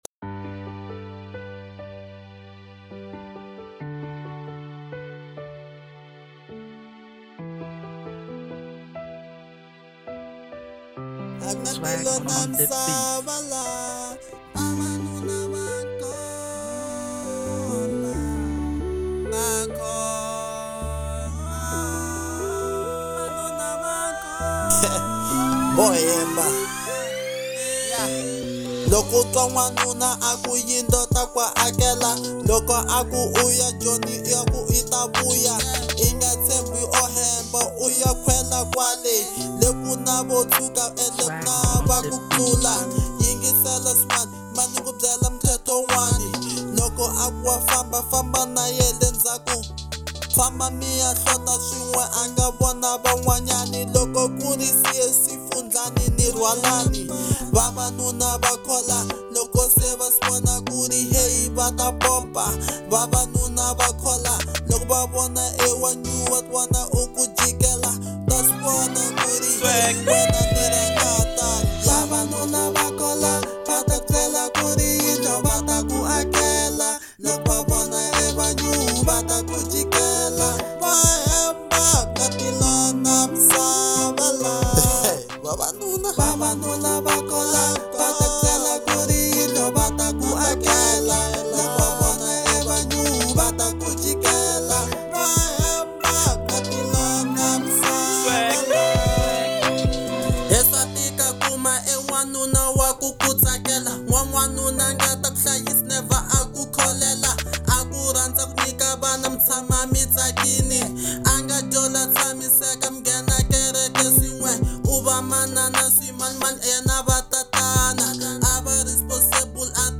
03:13 Genre : Hip Hop Size